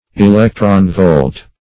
electron-volt - definition of electron-volt - synonyms, pronunciation, spelling from Free Dictionary